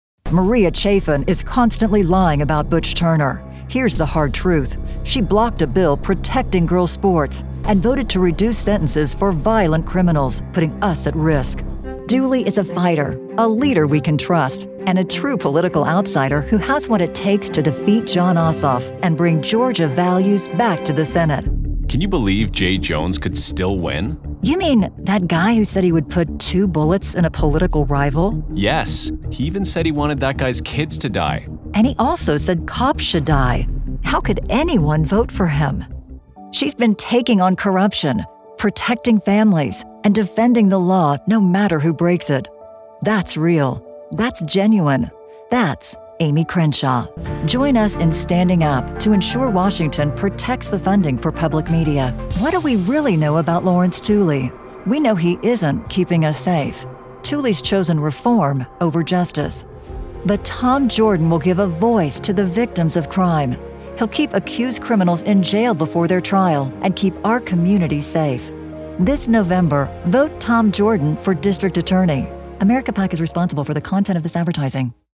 Powerful Republican Voices
Voice actors with deep experience, pro home studios and Source Connect.
FEMALE